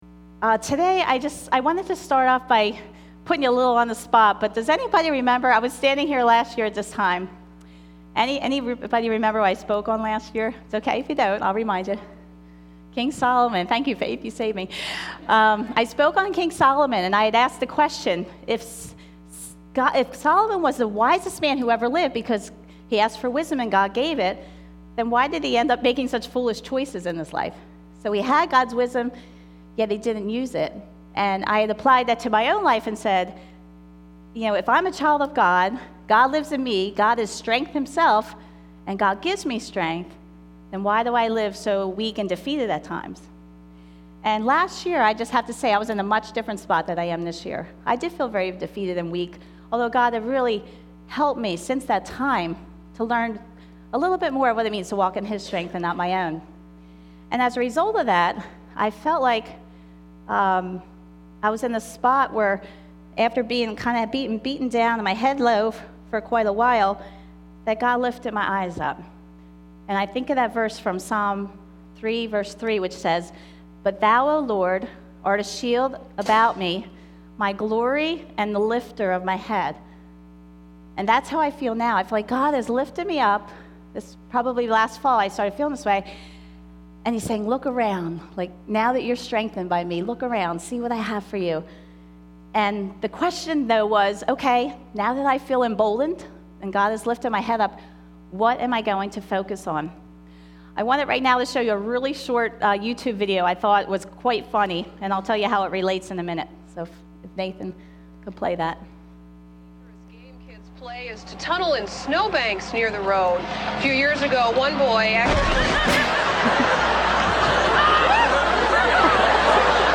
Mother's Day message 2013